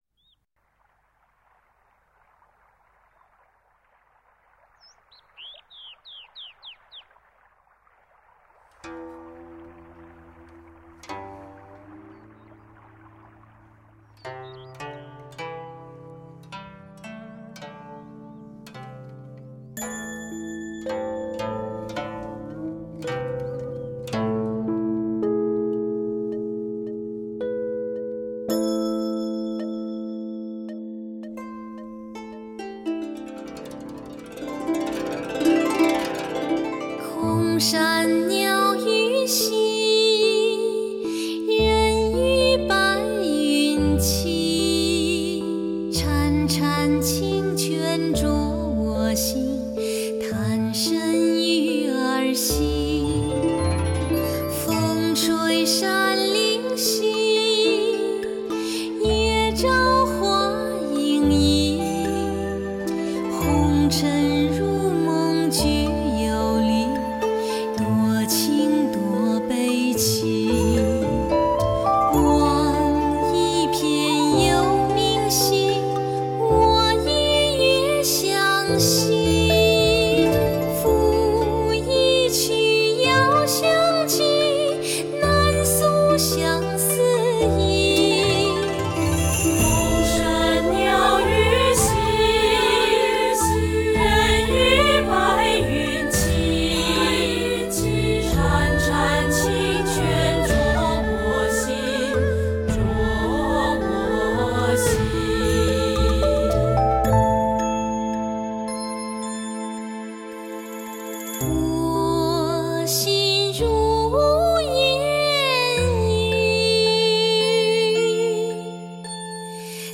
一张音乐性非常强的人声唱片，“让歌声回归音乐的本质”可以说是对该张唱片的最好解释。
这是一张充满东方音乐意韵的人声专辑，流行音乐元素带入专业的发烧听感之中，融合得无懈可击，值得一次次细心品味……